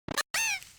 Cri de Matourgeon dans Pokémon Écarlate et Violet.